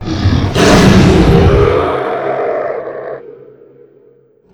TheExperienceLight/roar.wav at master
roar.wav